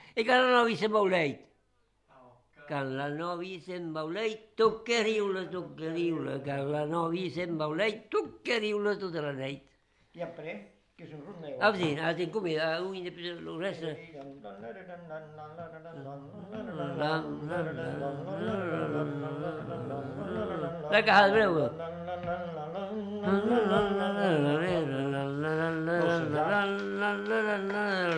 Lieu : Vielle-Soubiran
Genre : chant
Effectif : 1
Type de voix : voix d'homme
Production du son : chanté ; fredonné
Danse : rondeau